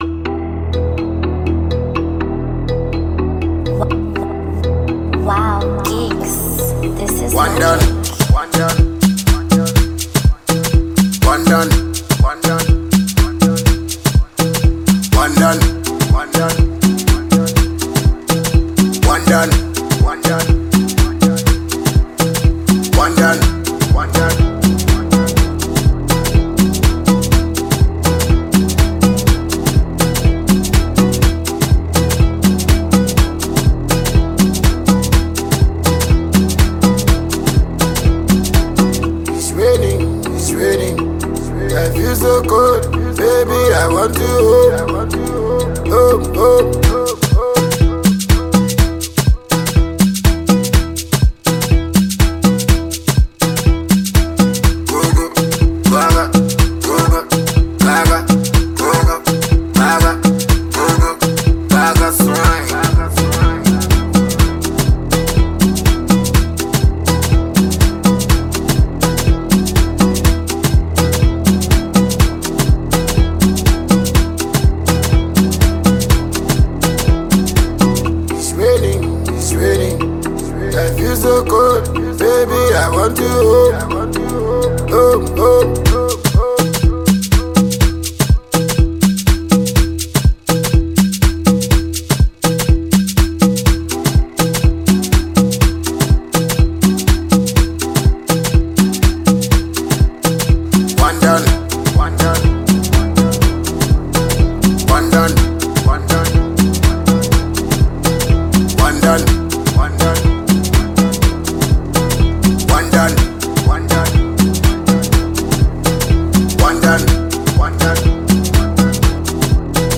Ghanaian award-winning dancehall